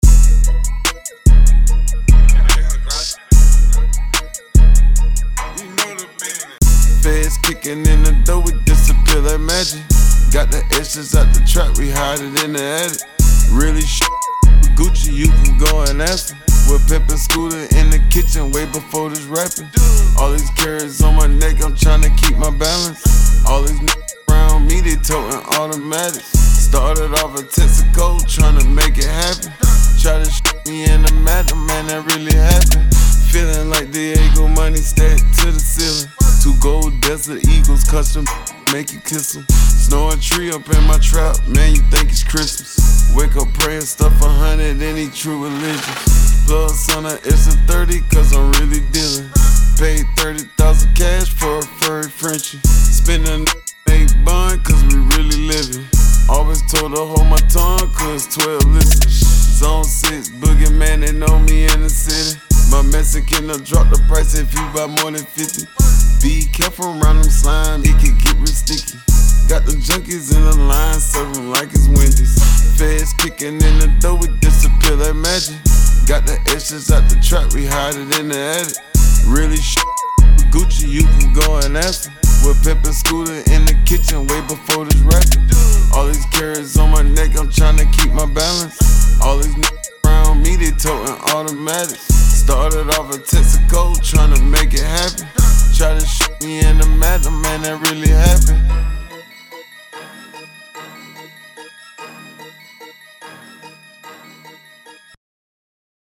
Hiphop
dope boy music